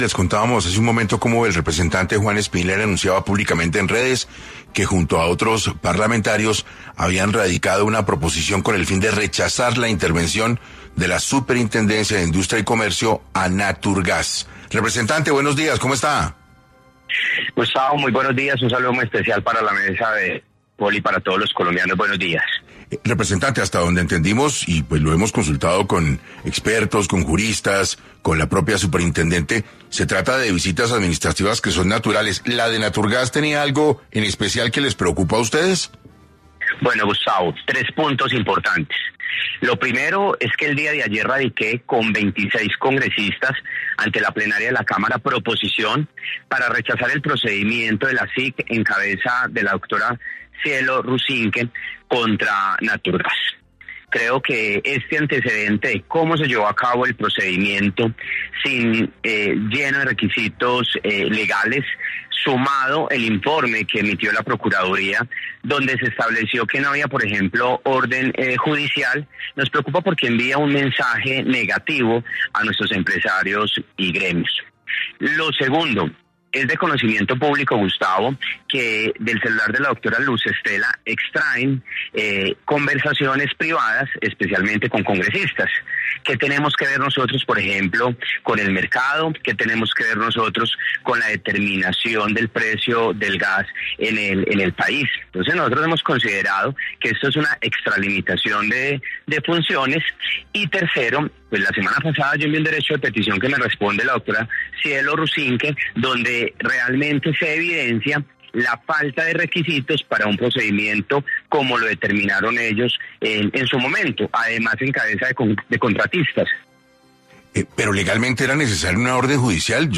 Hoy en entrevista para 6AM, el Representante Juan Espinal explicó sus razones por las que cree que hay abusos y extralimitaciones de la SIC hacia las empresas de gas y la oposición del actual Gobierno.